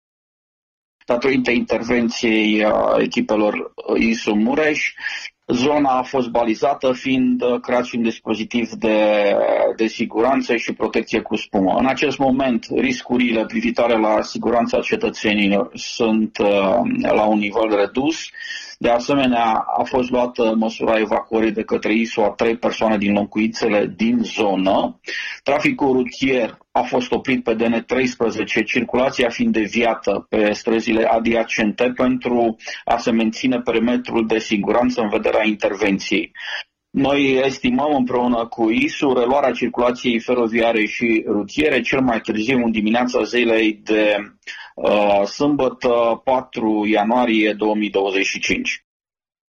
Prefectul județului, dr. Ovidiu Butuc, a declarat pentru Radio Târgu Mureș că circulația feroviară în zonă va fi reluată cel târziu mâine dimineață.